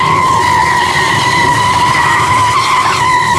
tyres_asphalt_skid.wav